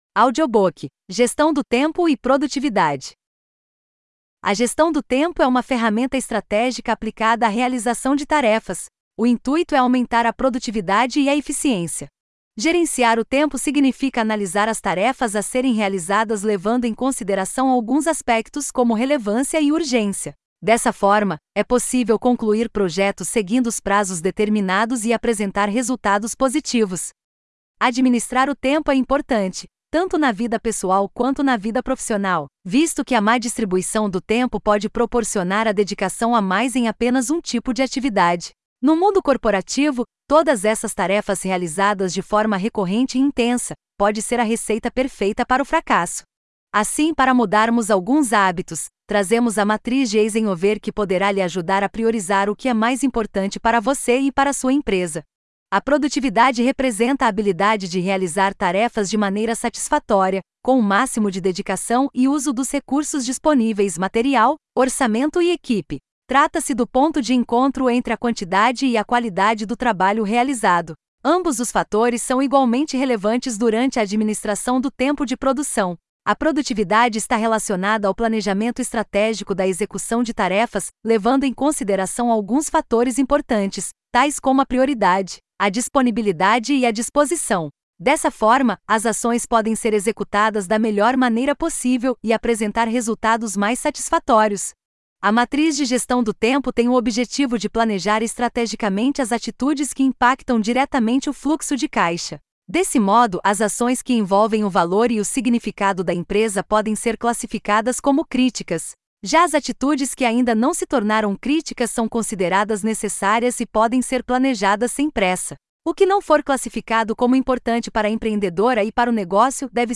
audiobook- gestao-de-tempo-e-produtividade.mp3